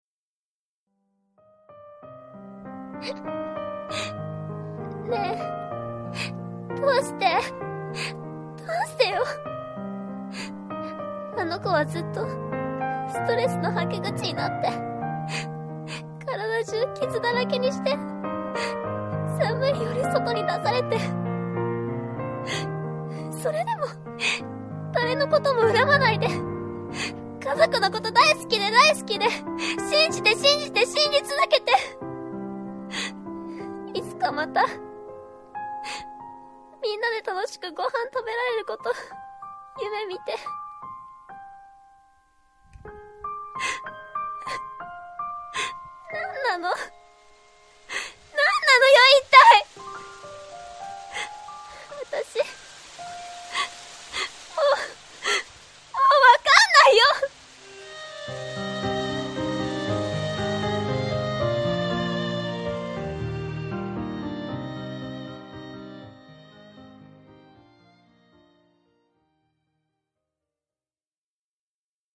katari2-natural-rain.mp3